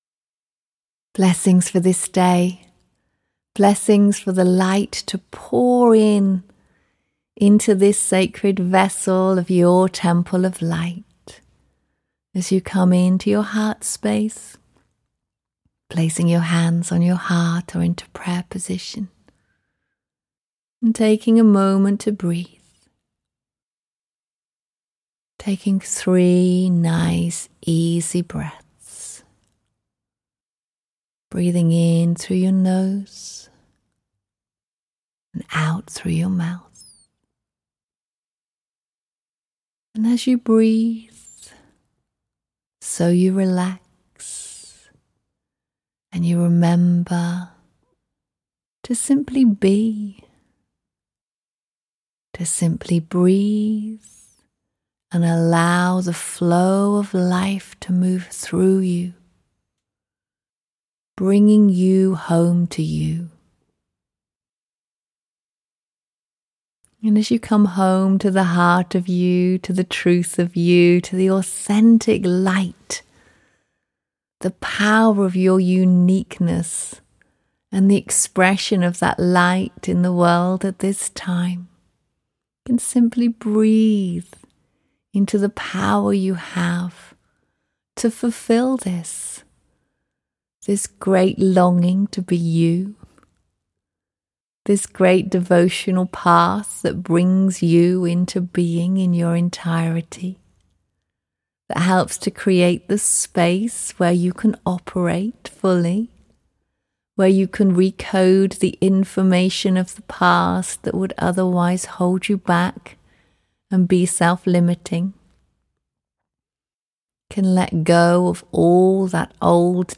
Beautiful daily offerings, bringing peaceful grounded support for everyday life. Transform from the inside out. Build resilience and anchor true peace in your body, heart and mind with these grounding meditations.